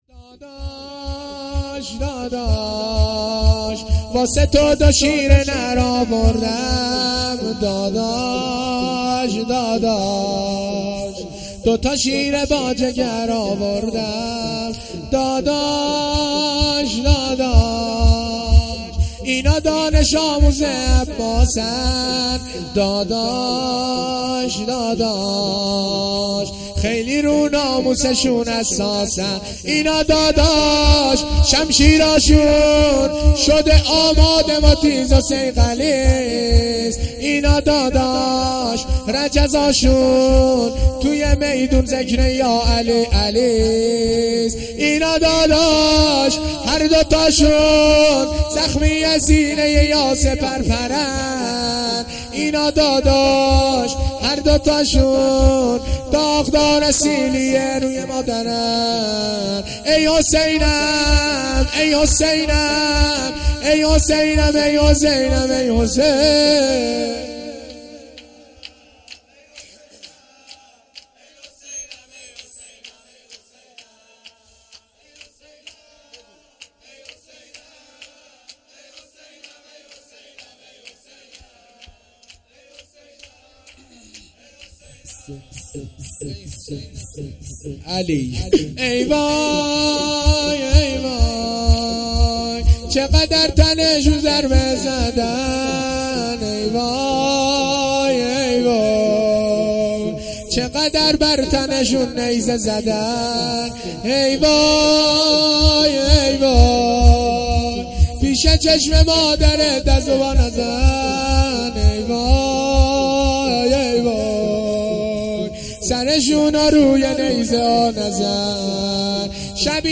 شور
محرم الحرام ۱۴۴۳